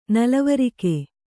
♪ nalavarike